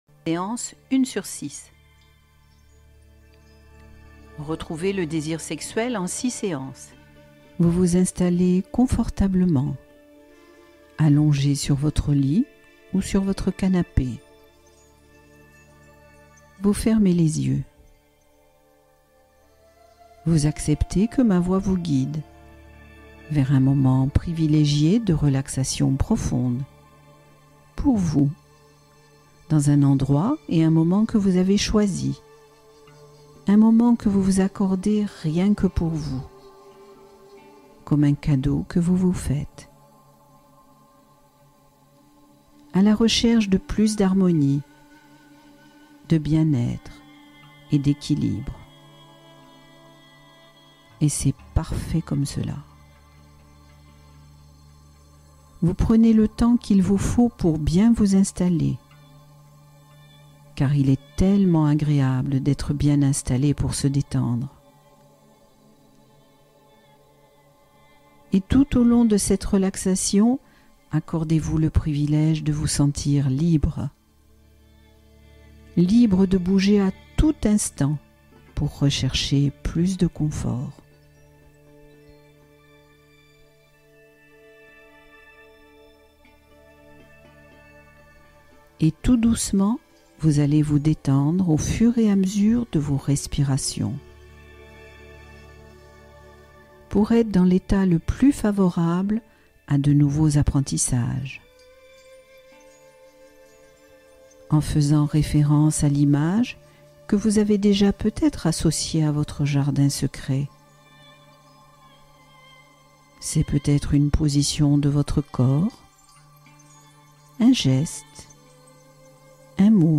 Sommeil retrouvé : hypnose pour mettre fin aux insomnies